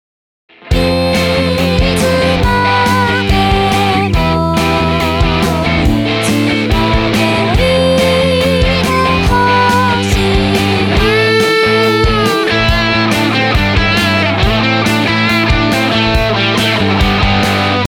ギターソロと３つにわけてレコーディングしました。
そして録音したギターを聞きながらベースもKONTACTの音色ソフトでサラっと入れます。
ボーカロイドを入れる
ドラム、ギター、ベース、ボーカルを入れたら
今回は、ボーカルにEQをかけてボリュームも上げていきました。
３０分で完成した曲